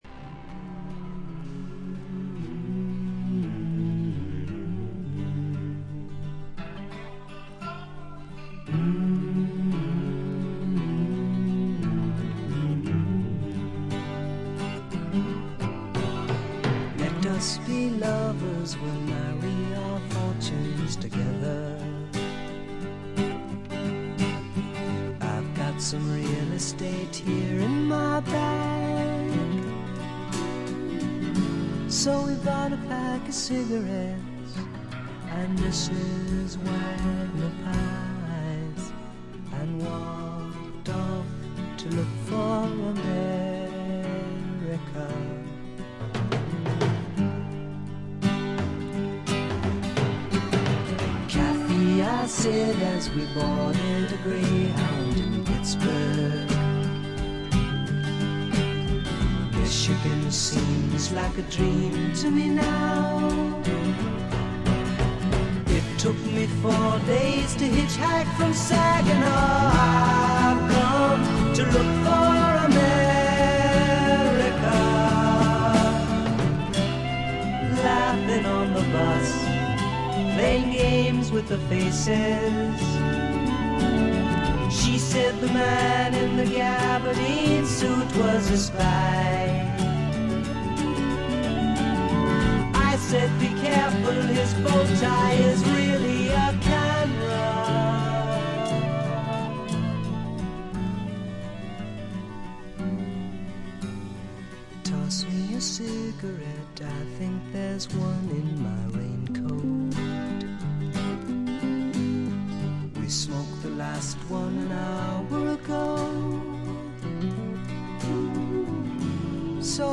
静音部で軽微なチリプチ少々。
試聴曲は現品からの取り込み音源です。